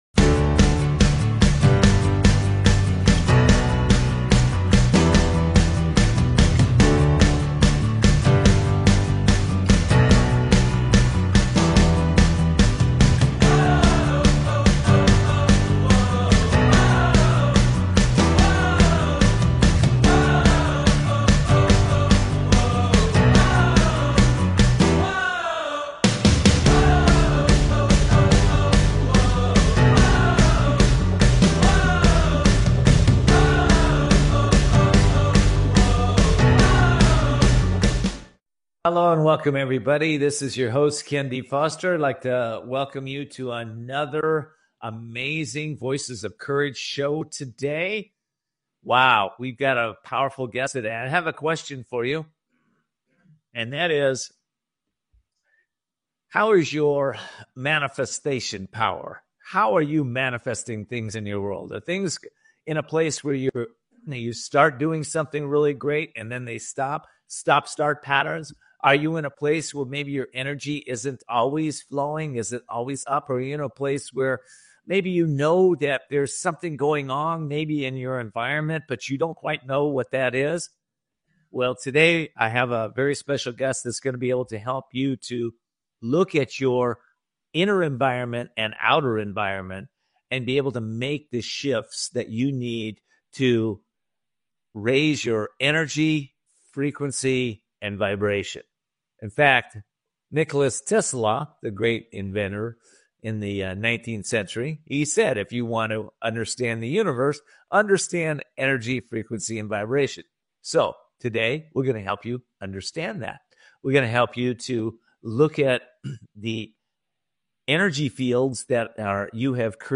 Talk Show Episode, Audio Podcast, Voices Of Courage and S4EP5, Energize Your Environment with Marie Diamond on , show guests , about S4EP5, categorized as Business,Health & Lifestyle,Love & Relationships,Psychology,Mental Health,Personal Development,Self Help,Inspirational,Motivational